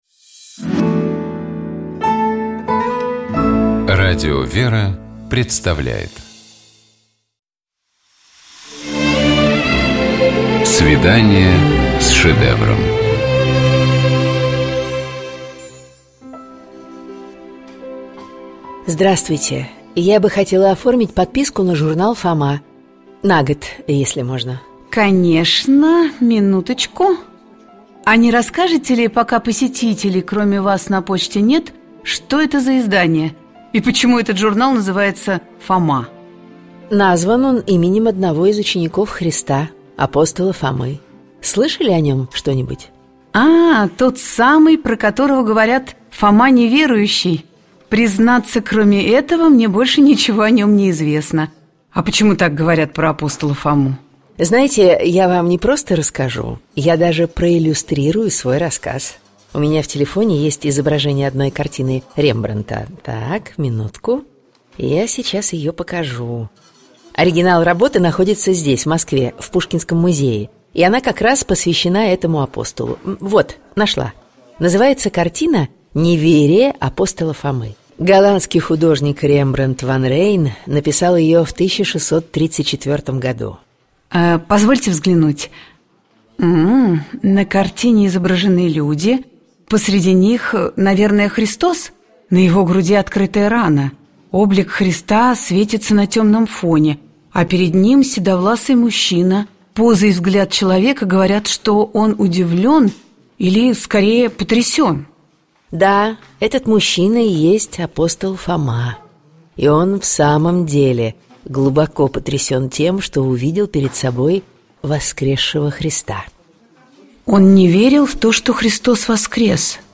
Богослужебные чтения - Радио ВЕРА